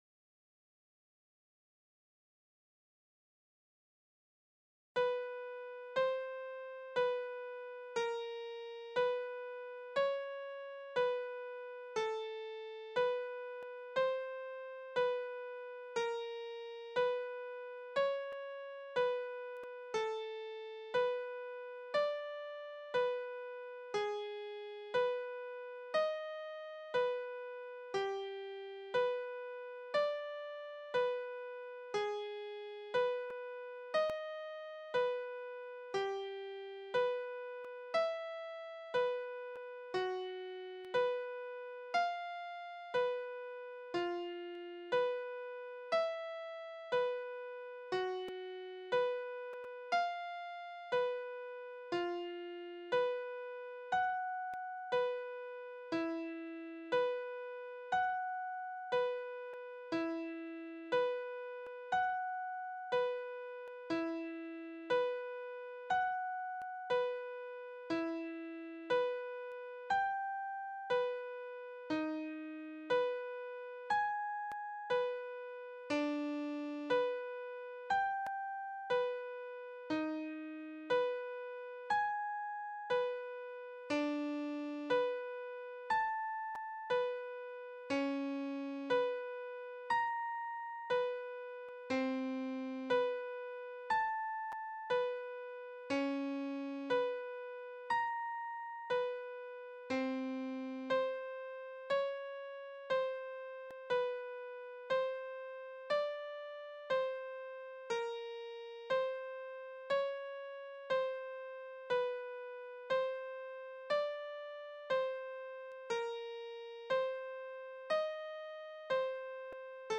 - Intervals (Lento!) -